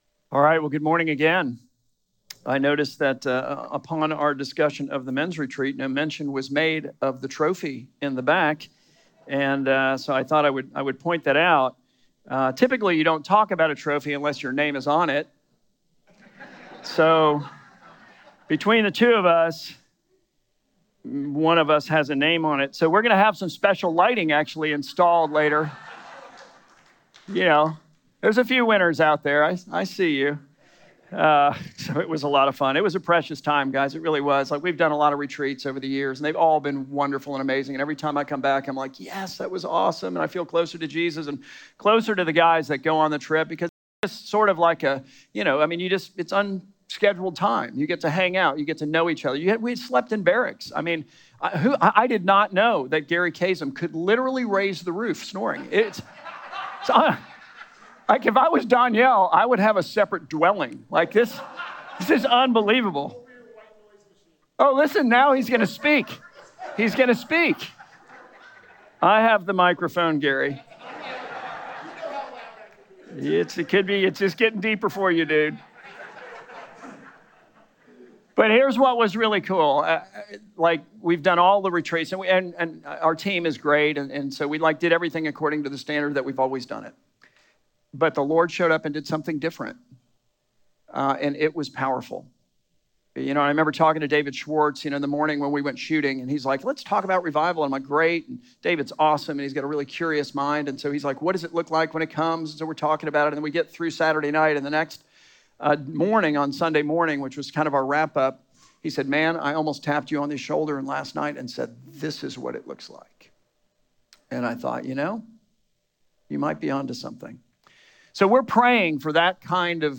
Rio Vista Church Sermons